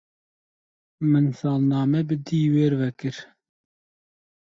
Pronounced as (IPA) /sɑːlnɑːˈmɛ/